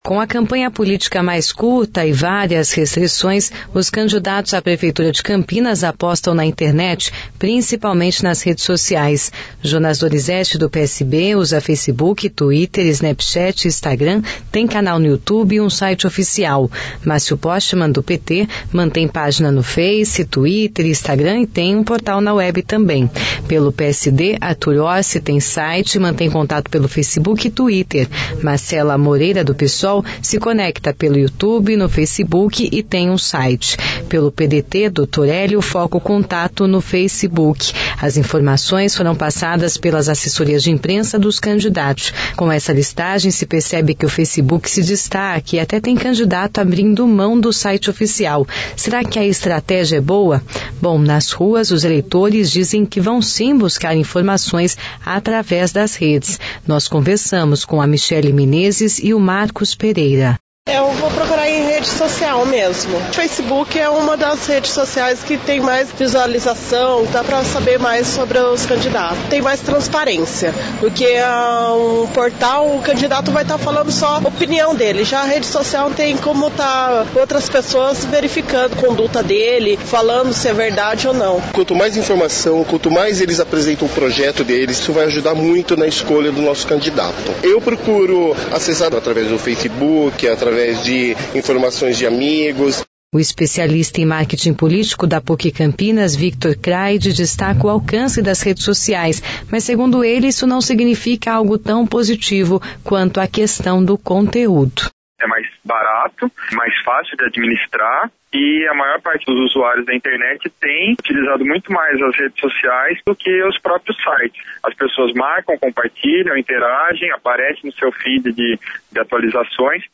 Bom, nas ruas, os eleitores dizem que vão sim buscar informações através dessas redes.